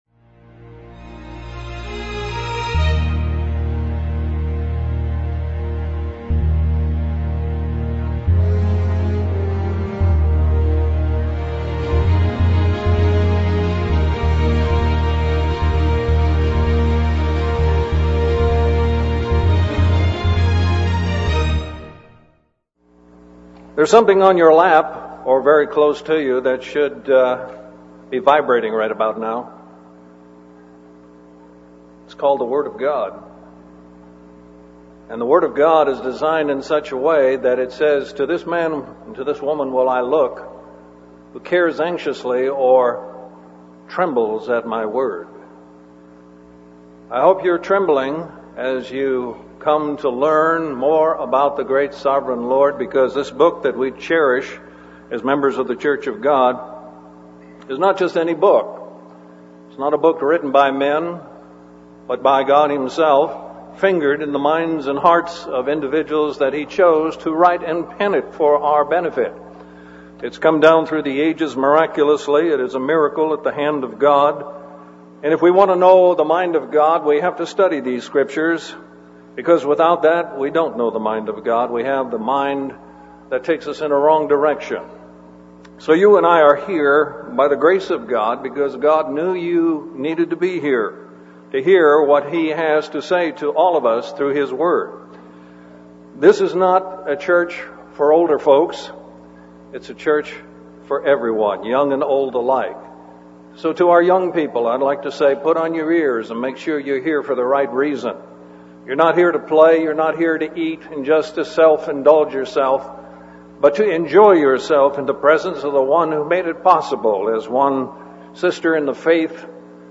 This sermon was given at the Panama City Beach, Florida 2009 Feast site.